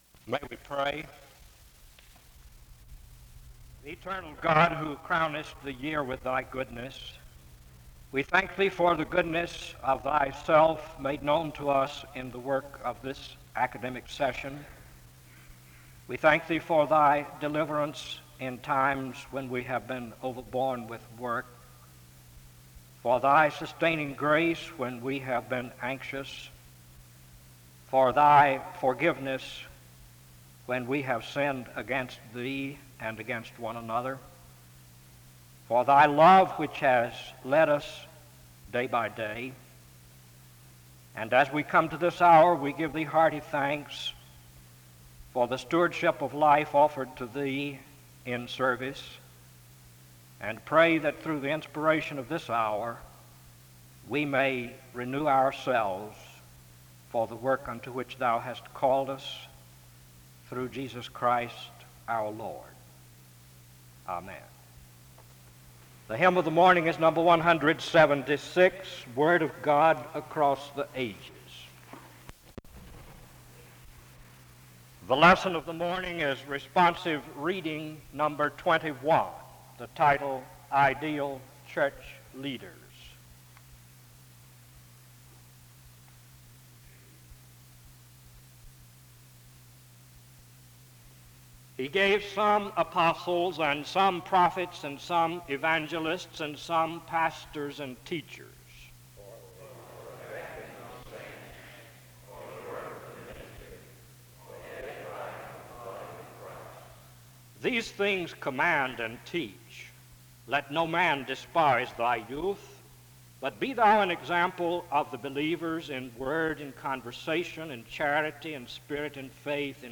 SEBTS Chapel
The service begins with an opening word of prayer from 0:00-1:05. A responsive reading takes place from 1:15-3:16.
Closing remarks are given from 25:10-26:20.